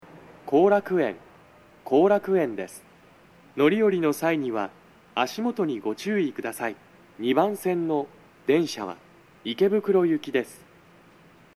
足元注意喚起放送が付帯されており、粘りが必要です。
到着放送2
JVC横長型での収録です。